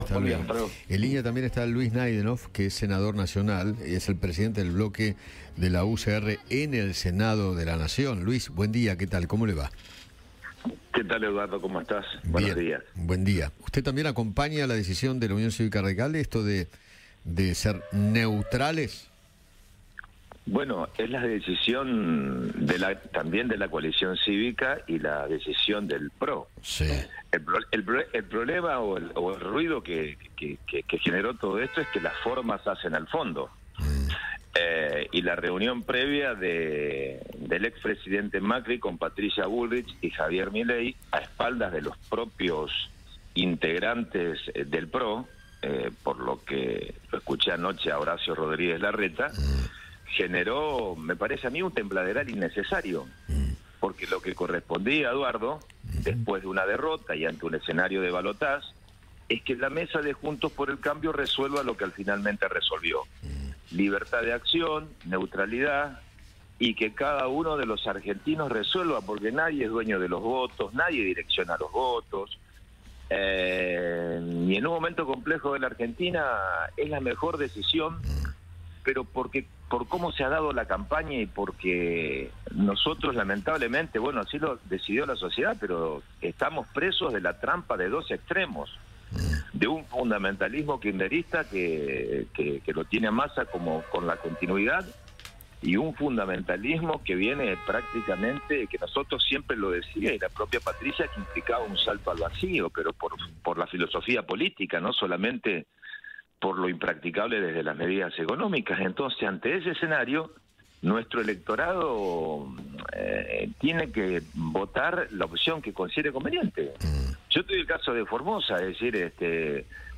Luis Naidenoff, senador nacional de la UCR, conversó con Eduardo Feinmann sobre el apoyo de Patricia Bullrich y Mauricio Macri a Javier Milei en el balotaje.